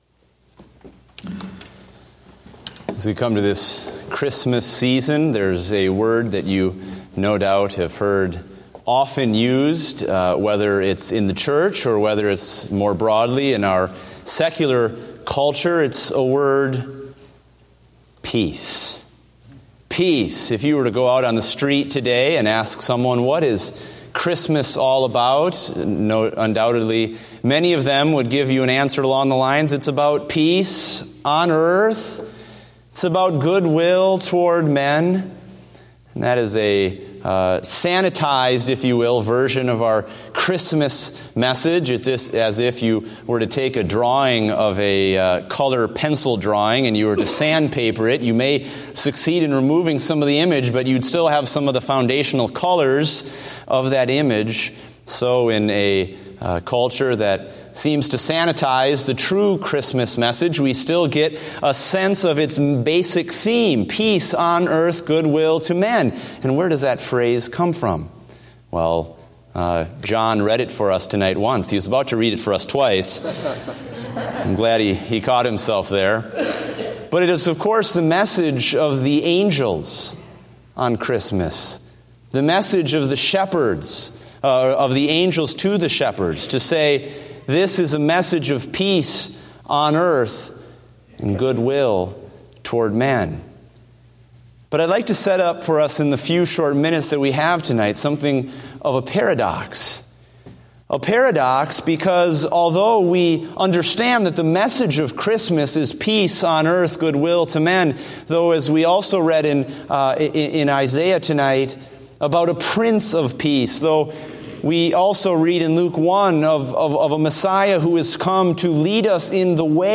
Date: December 4, 2015 (Special Event)